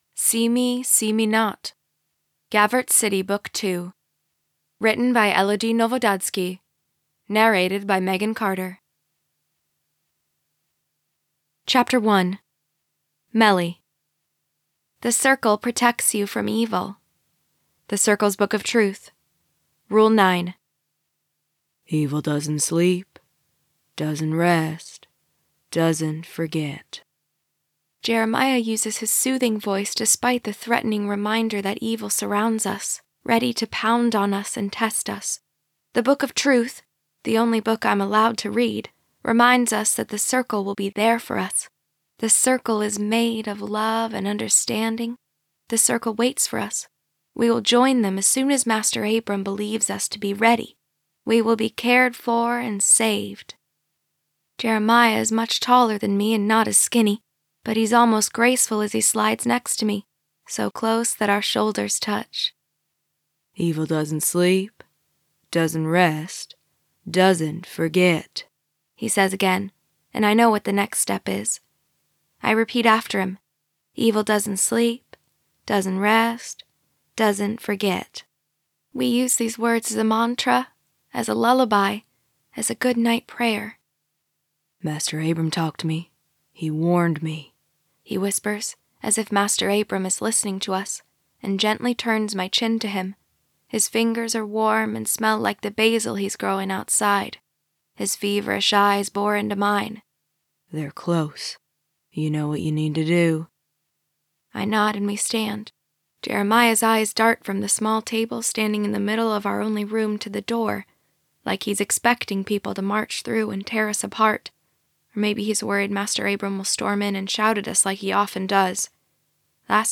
For fans of Criminal Minds comes an audiobook full of heartache, mystery, and romance.
Audio Retail sample of SEE ME, SEE ME NOT.